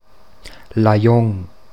Standarditalienische Form
[laˈjɔŋ]